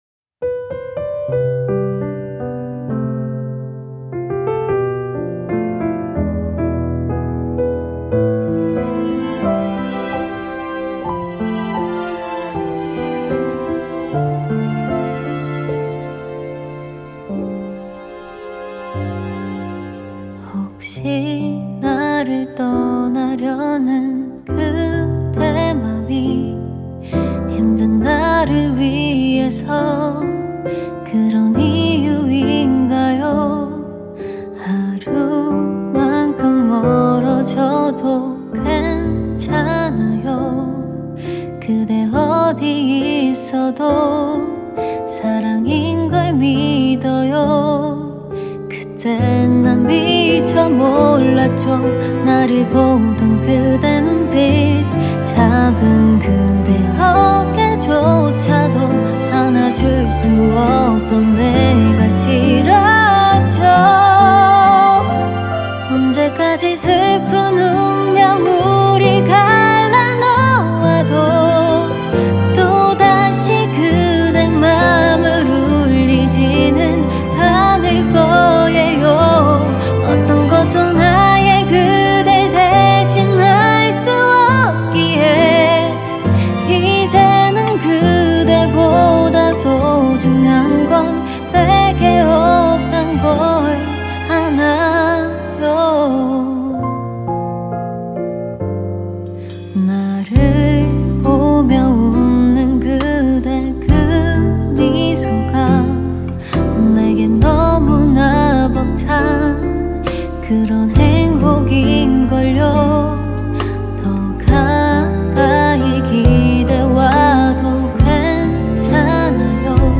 KPop
Label Ballad